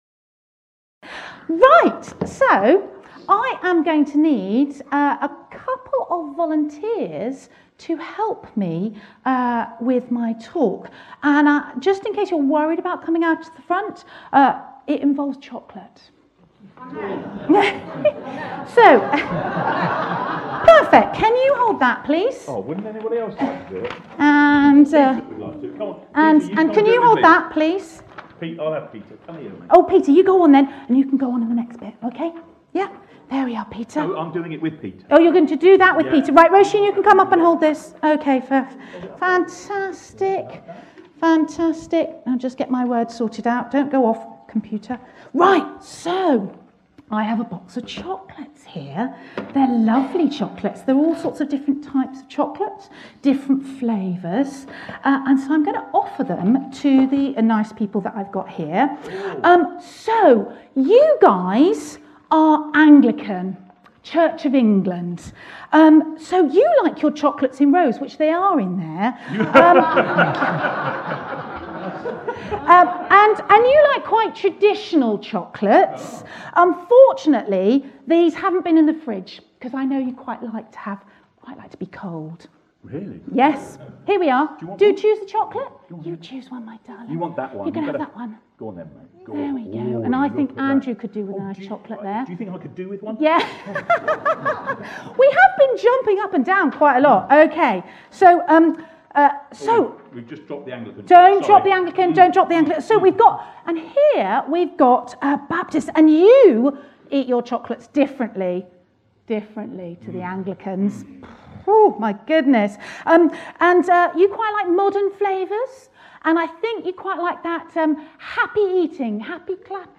The two churches in Seer Green met together for a joint service on 16th September.